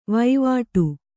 ♪ vaivāṭu